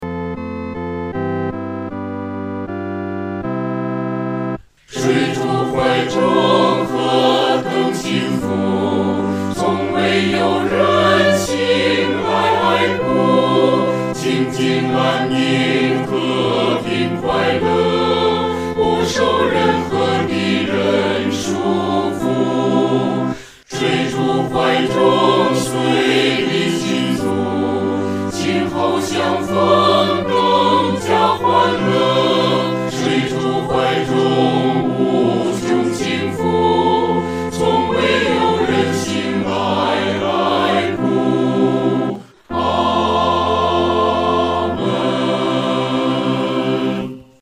合唱
四声
其旋律、和声构成无比宁静的气氛，在丧礼中给人莫大的安慰。